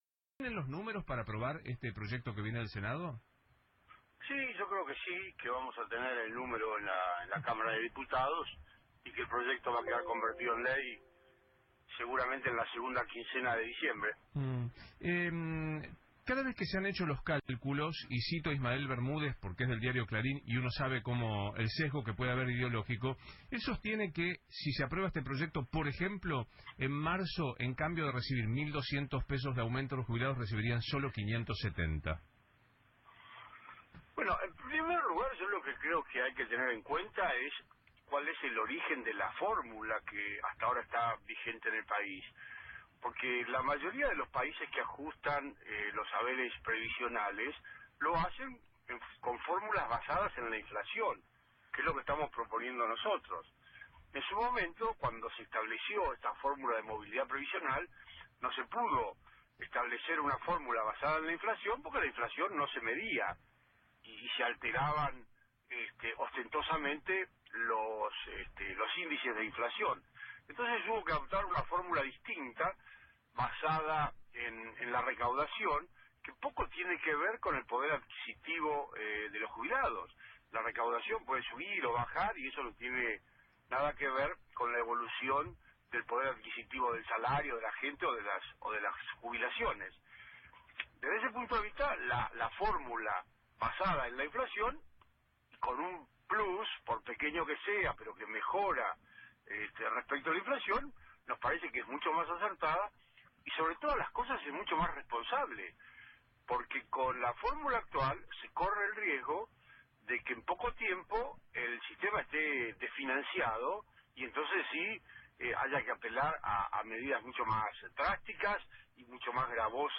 “La preocupación es que el incremento de las jubilaciones y pensiones esté siempre por encima del índice del costo de vida para que, respecto de la inflación, los jubilados no pierdan poder adquisitivo en comparación con la inflación y con el incremento de los precios, y es lo que la nueva fórmula garantiza”, dijo Tonelli, durante una entrevista en radio La Red.
pablo-tonelli.mp3